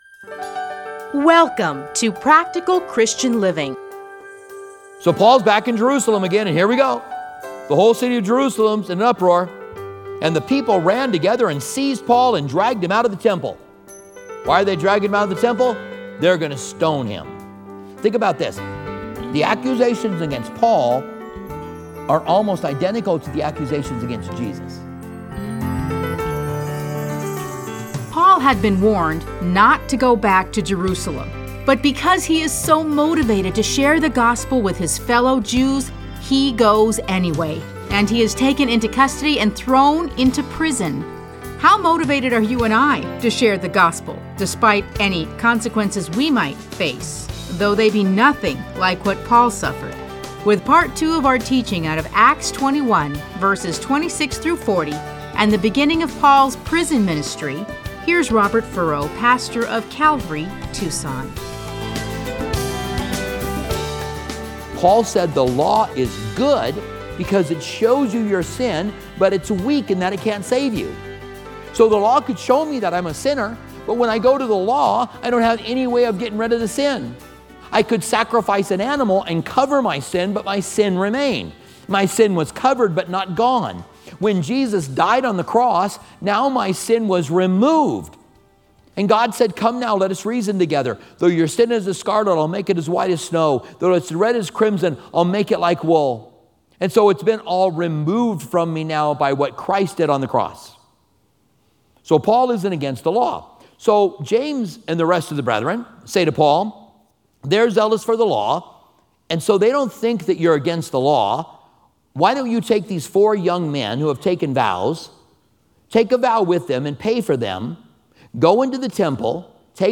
Listen to a teaching from Acts 21:26-40.